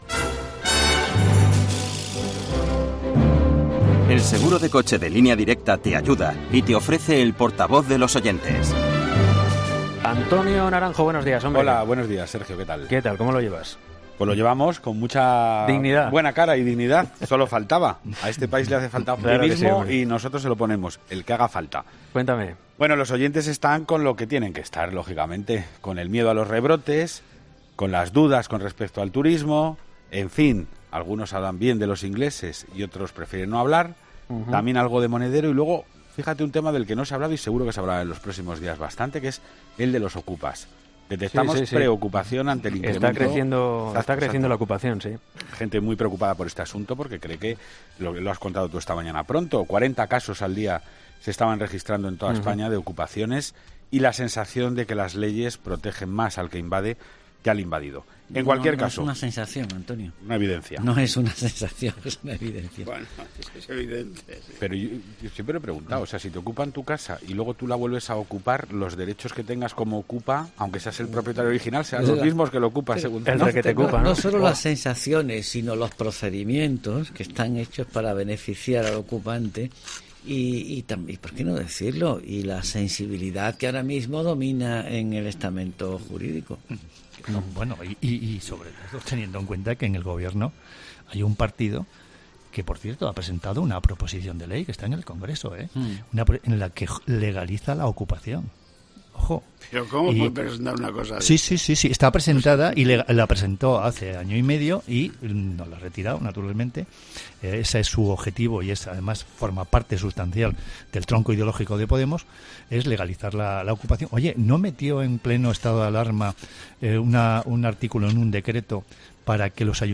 Los oyentes, de nuevo, protagonistas en 'Herrera en COPE' con su particular tertulia.
Avalancha de mensajes en el contestador de 'Herrera en COPE' con los temas del día